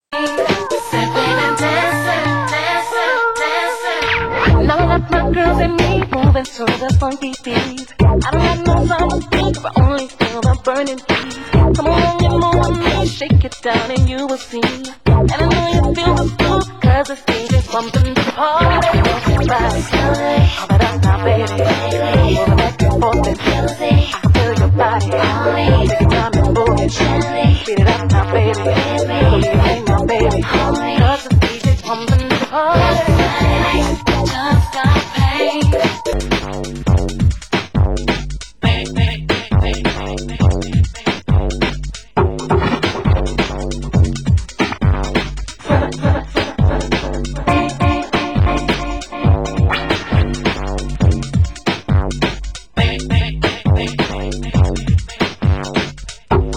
Genre: UK Garage
VOCAL MIX, VOCAL DUB